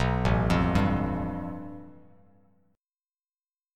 A7sus2#5 chord